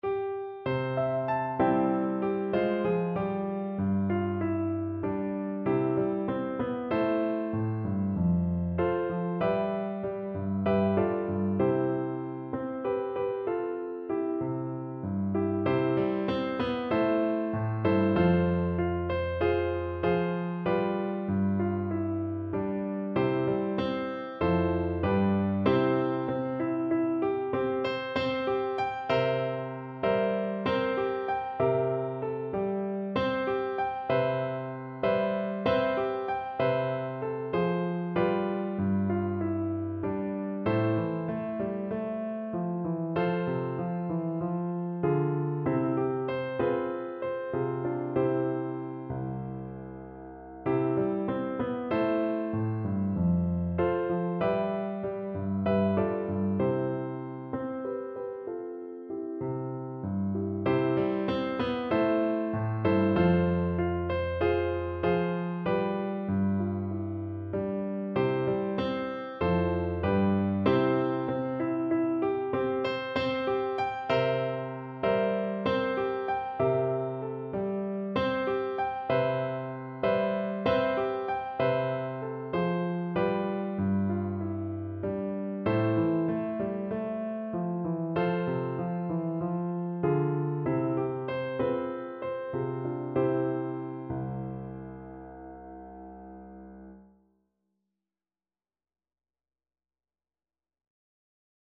Flute
C major (Sounding Pitch) (View more C major Music for Flute )
Moderato espressivo =c.96
Traditional (View more Traditional Flute Music)
Flute pieces in C major